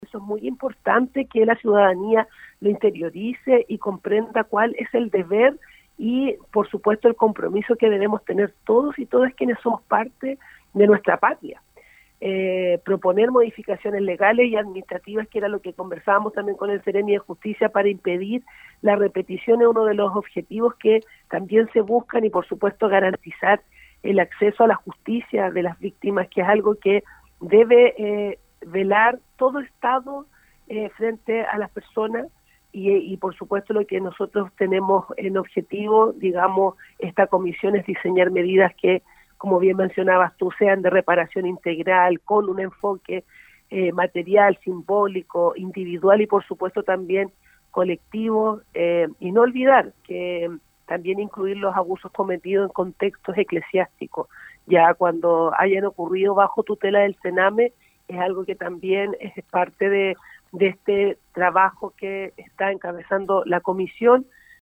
La seremi recalcó que la instancia tiene por propósito “reconocer públicamente a las víctimas y establecer las vulneraciones que vivieron, poder describir y analizar las violaciones a los derechos humanos que ocurrieron bajo custodia del Estado de Chile”.